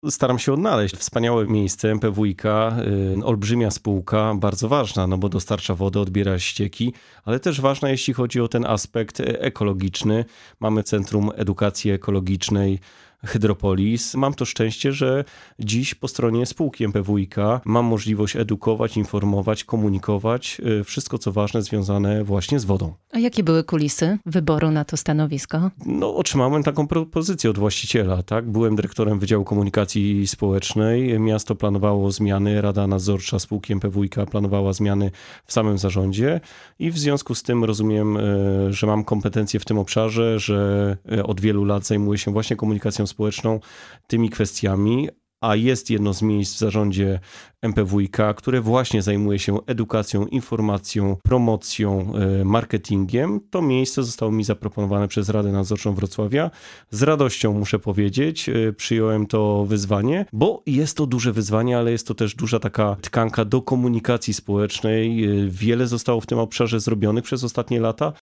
Pytamy w wywiadzie.